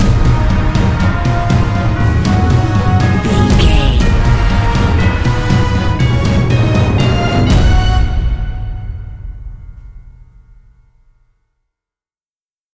Epic / Action
Aeolian/Minor
E♭
strings
orchestral
orchestral hybrid
dubstep
aggressive
energetic
intense
bass
synth effects
wobbles
driving drum beat
epic